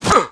role1_wound1.wav